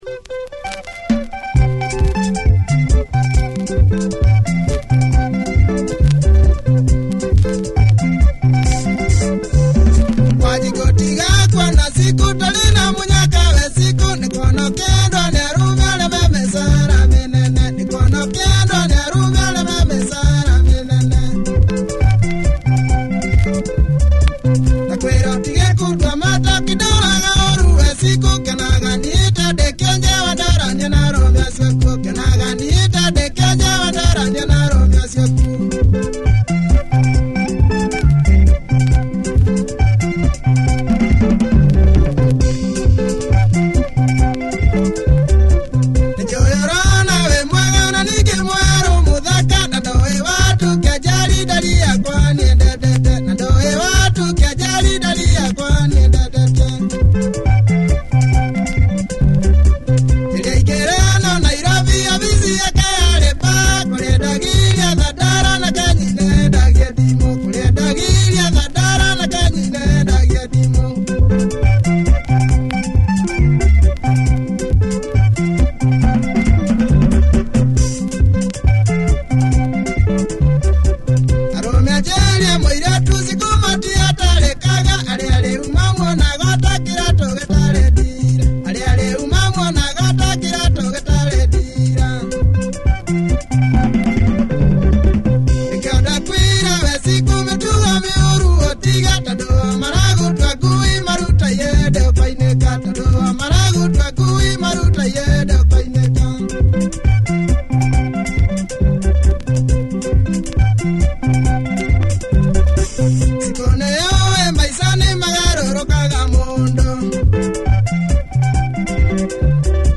Tight Kikuyu benga with a slight disco touch. Club appeal!